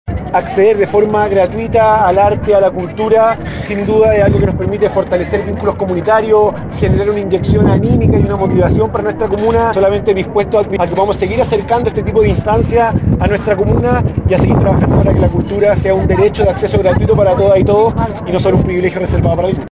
Al respecto el alcalde de Maipú Tomás Vodanovic, se mostró contento que los maipucinos tras las malas noticias que han afectado a la comuna puedan presenciar esta maravillosa obra
vodanovic-carmina.mp3